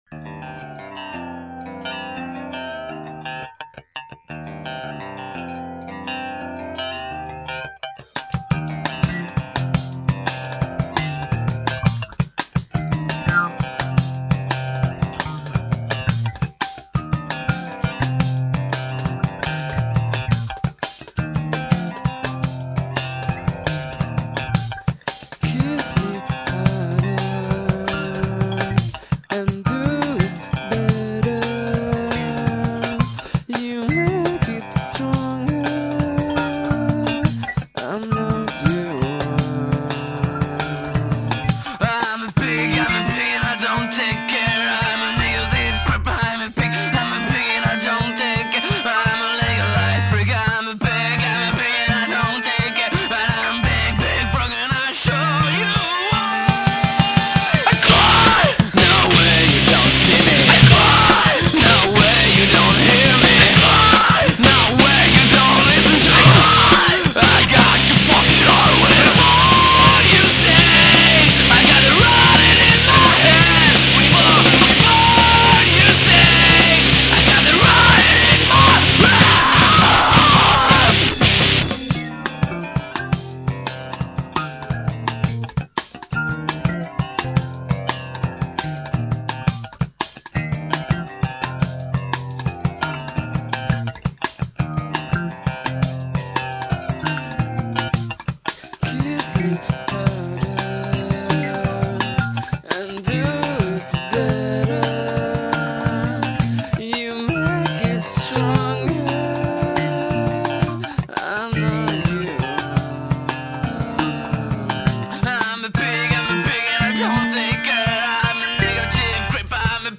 Pop-core
Avec l'expérience, leur musique résonnent résolument rock.